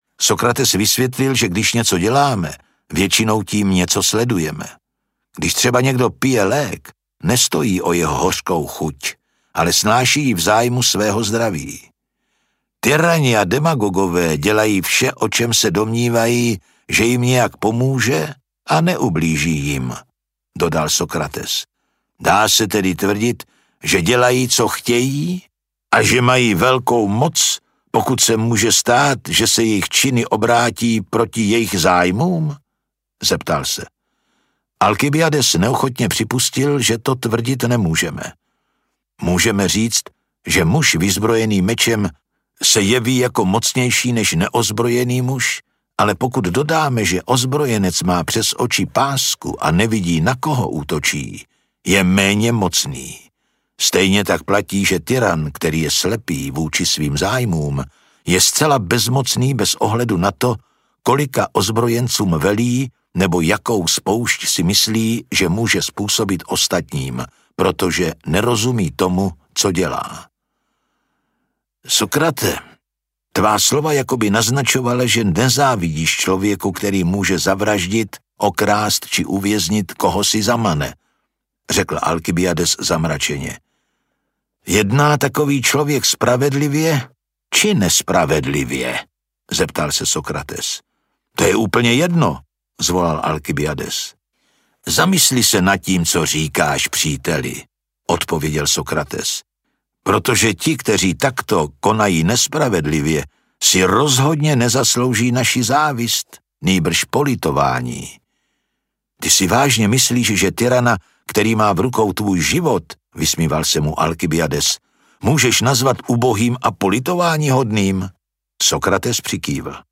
Ukázka z knihy
• InterpretZdeněk Junák